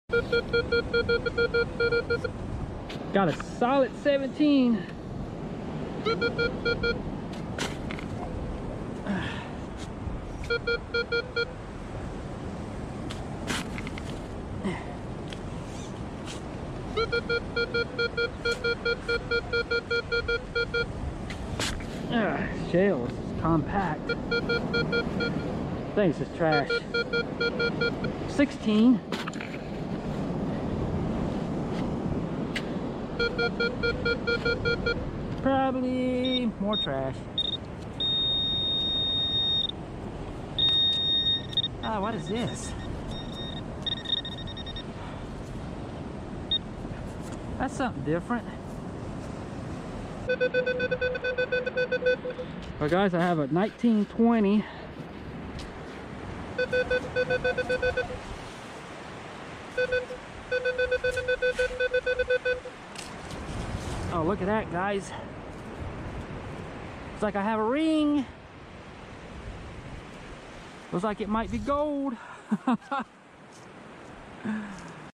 Out on the beach metal detecting in search of lost treasure digging around in the sand,. I'm using a Minelab Equinox 800 metal detector..